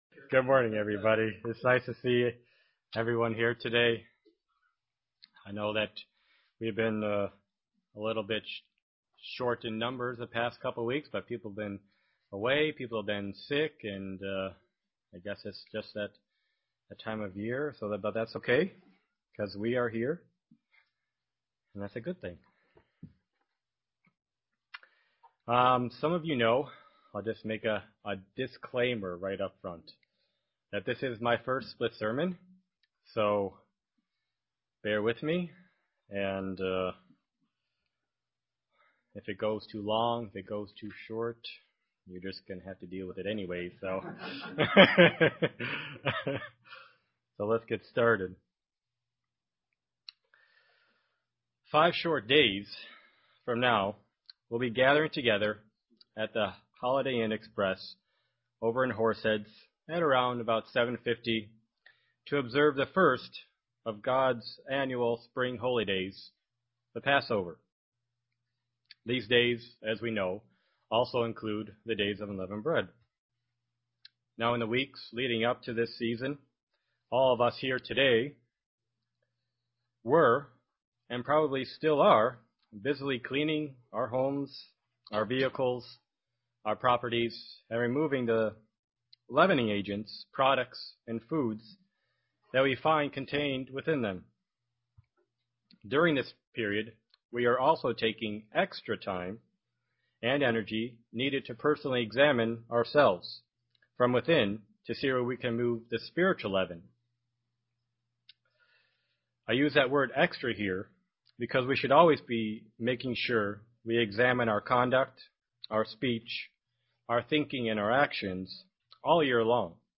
Print Having and maintaining the footwashing attitude UCG Sermon Studying the bible?